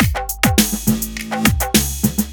103 BPM Beat Loops Download